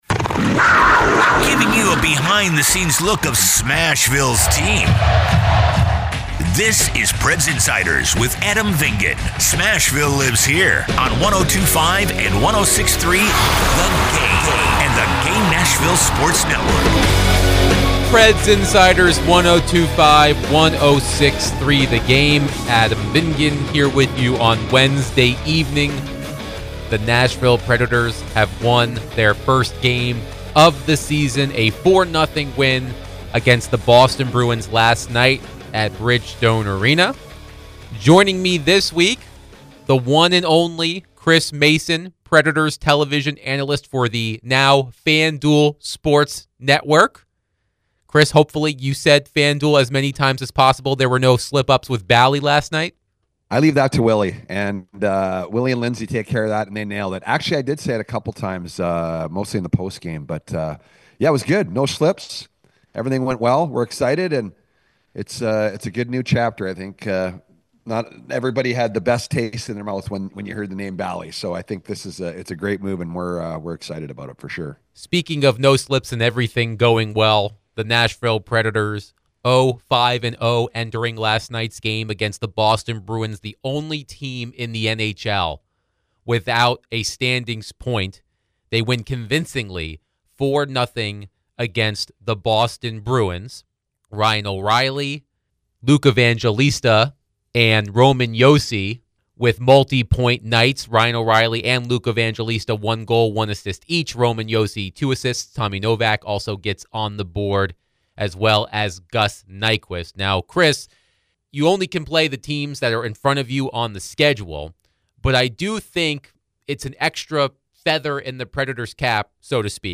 The guys chat about some encouraging signs they've noticed & what's to come.